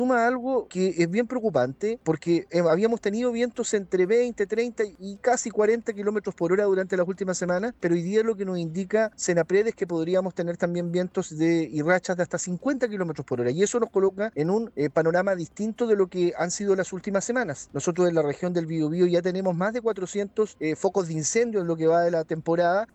A dichas temperaturas se suman las rachas de viento que alcanzarán los 50 km/hr, condiciones que las autoridades han señalado como preocupantes especialmente por la propagación de incendios forestales. Así lo señaló el delegado presidencial del Bío Bío, Eduardo Pacheco.